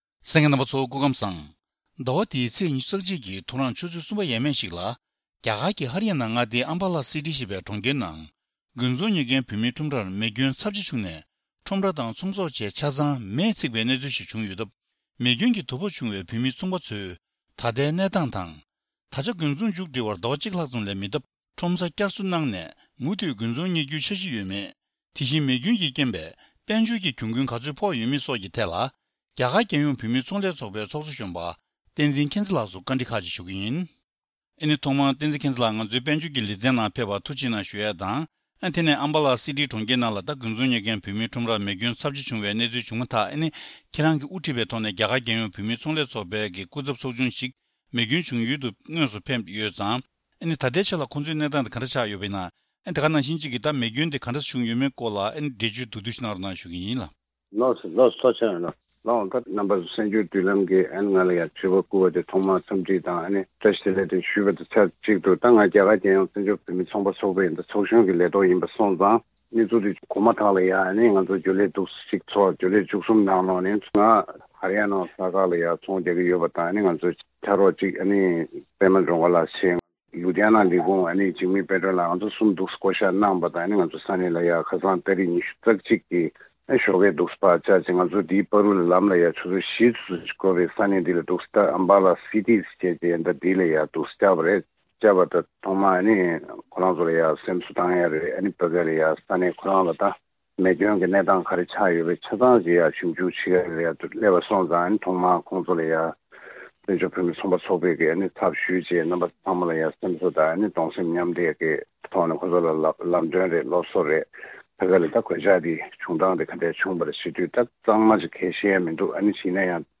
སྒྲ་ལྡན་གསར་འགྱུར།